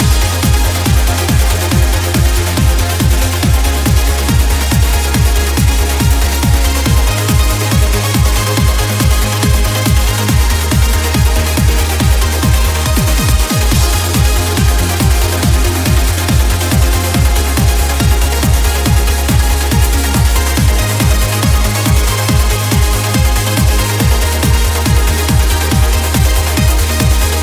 uplifting trance
Genere: trance, uplifting trance